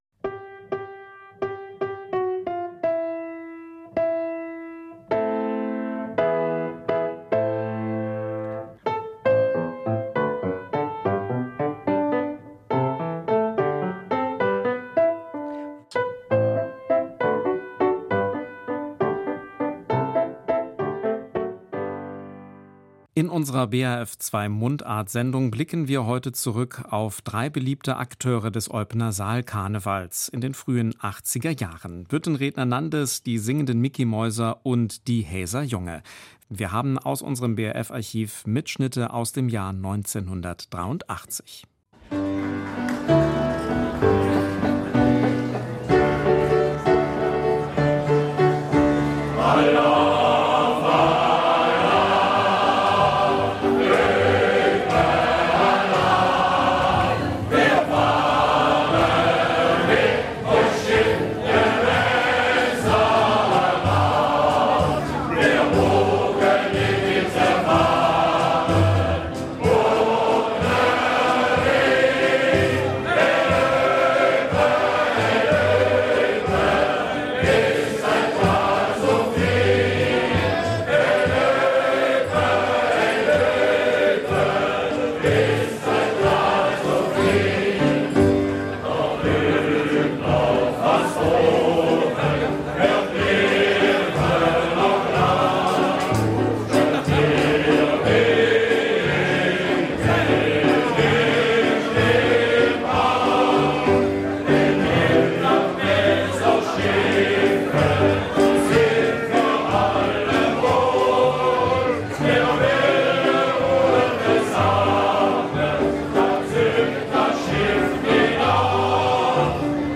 Die Sendung beginnt mit Eupens Karnevalshyme ''Öüpe Alaaf'', gesungen von den Hääser Jonge. Den Abschluss machen die singenden Micky-Mäuse. Alle Aufnahmen aus dem Jahr 1983 stammen aus dem BRF-Archiv.